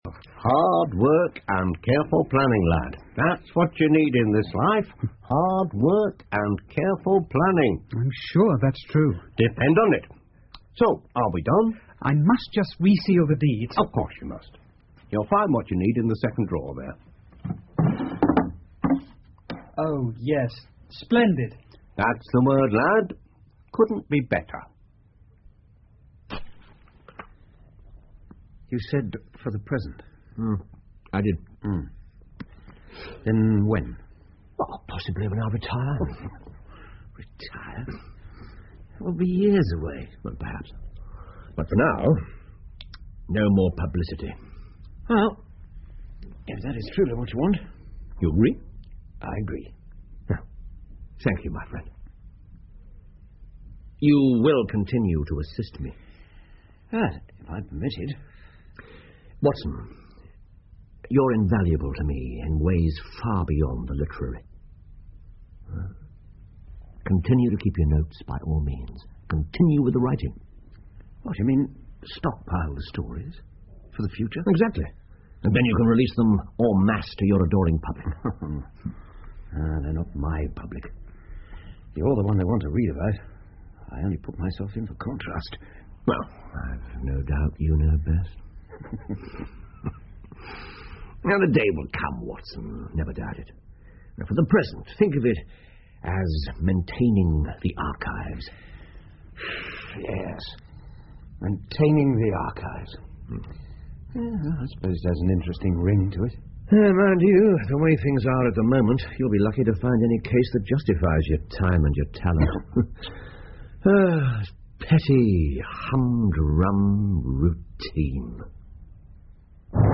在线英语听力室福尔摩斯广播剧 The Norwood Builder 3的听力文件下载,英语有声读物,英文广播剧-在线英语听力室